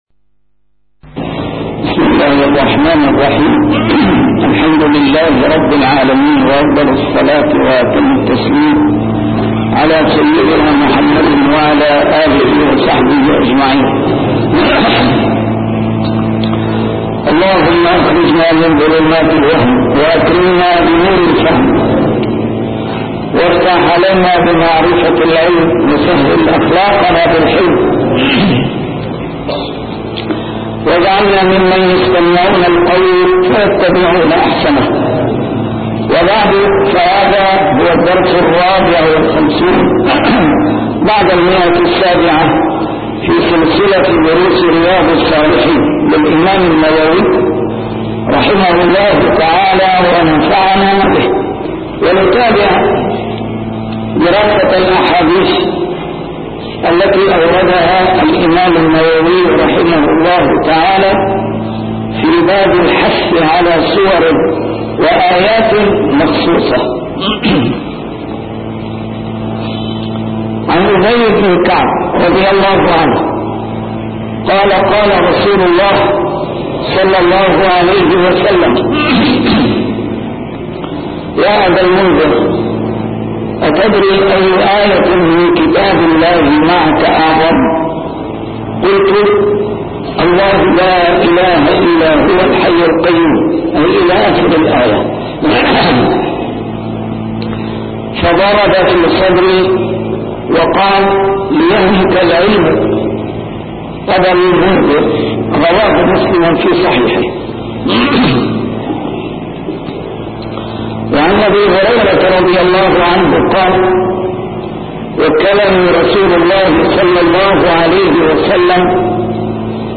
A MARTYR SCHOLAR: IMAM MUHAMMAD SAEED RAMADAN AL-BOUTI - الدروس العلمية - شرح كتاب رياض الصالحين - 754- شرح رياض الصالحين: الحث على سور وآيات مخصوصة